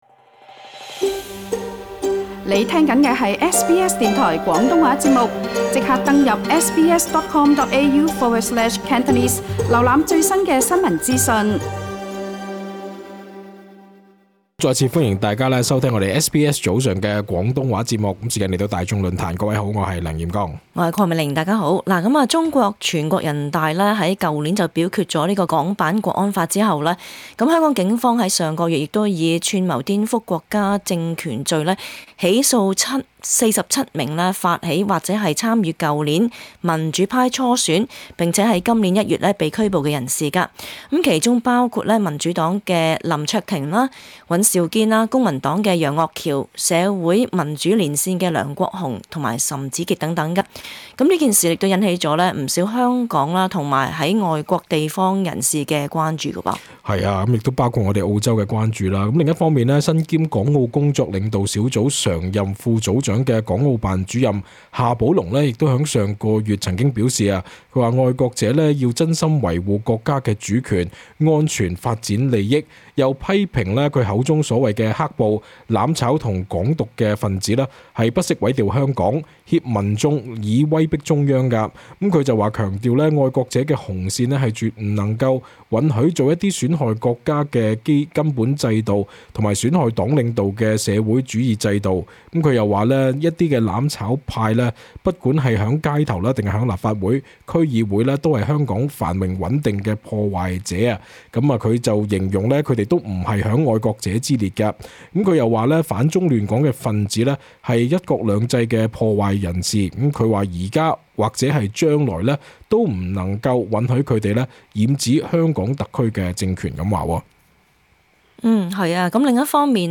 (本節目內嘉賓及聽眾意見並不代表本台立場 。)